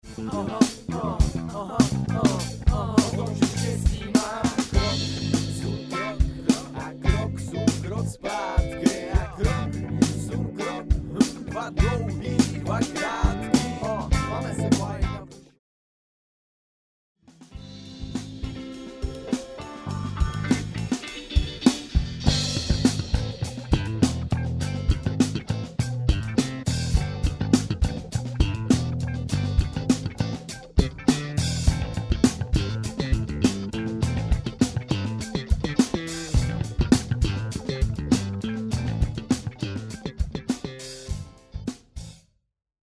Tak je to spíš takový preMix
ale pekne to je jen teda z toho virblu hrajou struny jak kdyby to byl jeden z cinelu big_smile .. btw nerikam ze je to spatne smile
Hraje poměrně hluboko, pokud ho nenaladíš výš a struník je hodně silný, to jo.